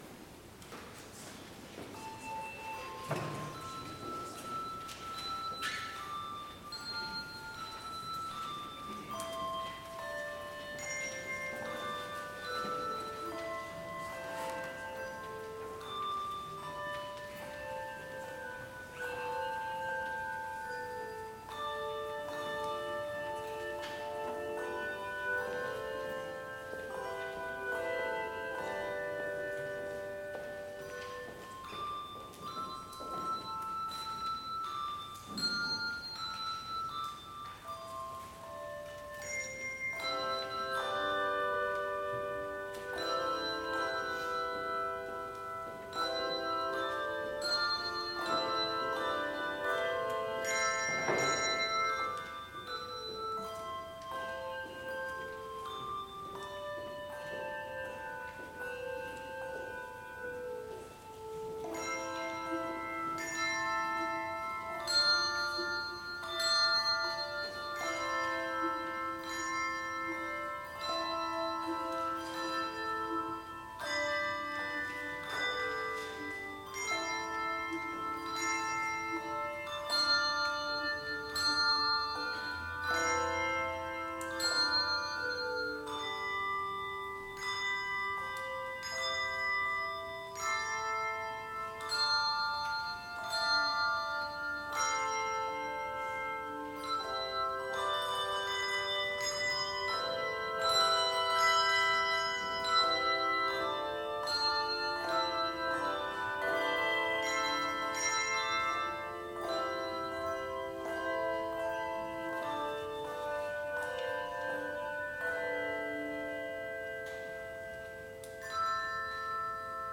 Complete service audio for Chapel - December 10, 2021